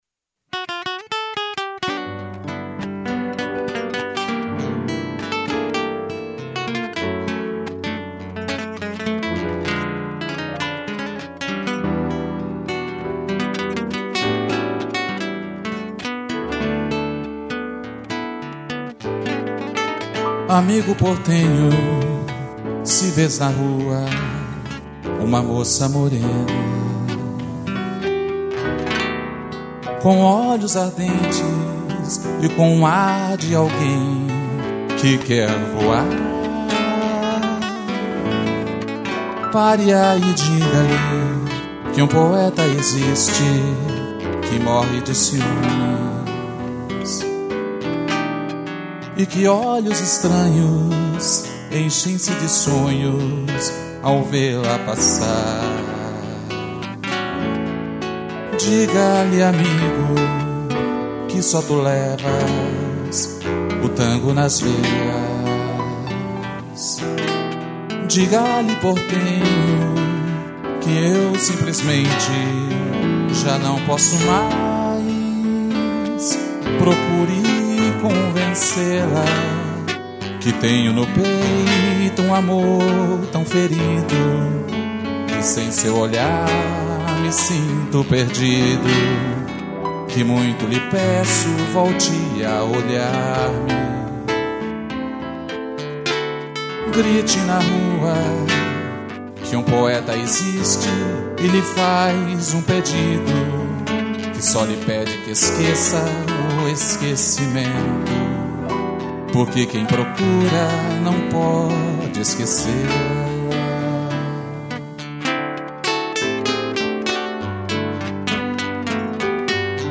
voz e violão
piano